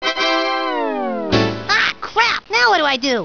sp_tada.wav